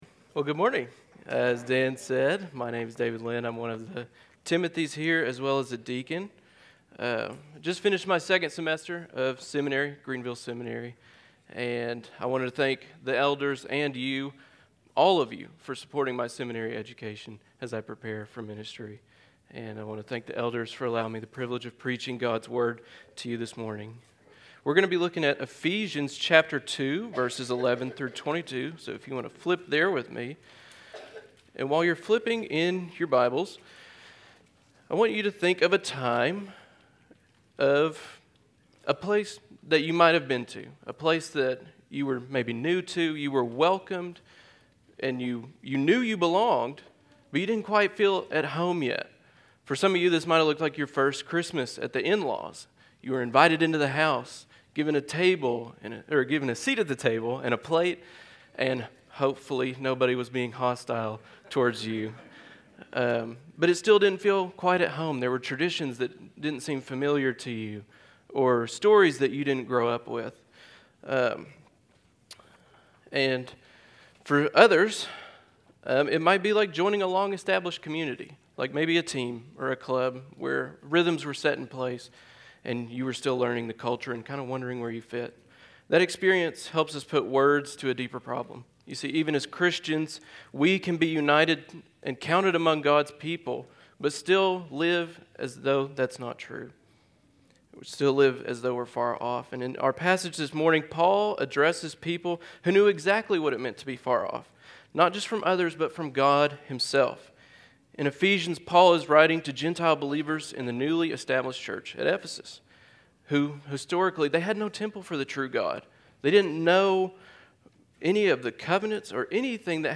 Central Hope sermon on Ephesians 2:11-22